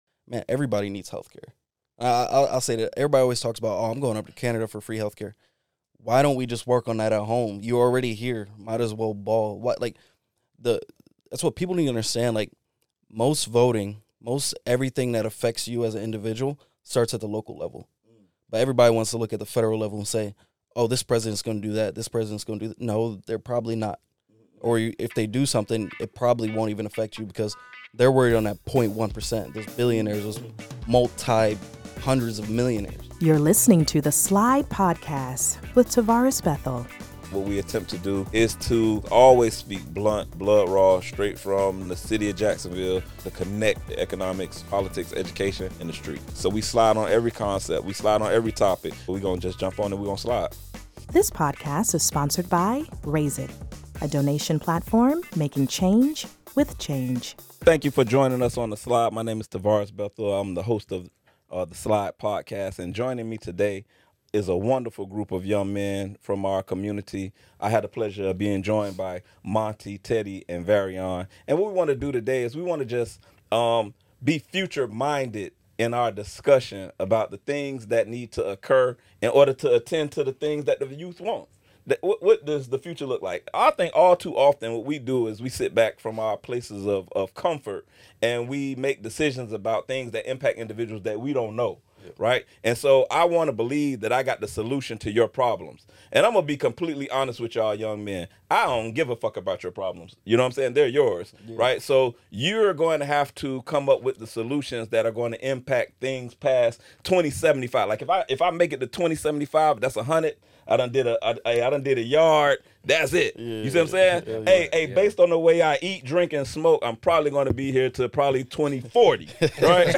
The episode is a candid discussion aimed at providing future generations with the insights and tools they need to create meaningful change.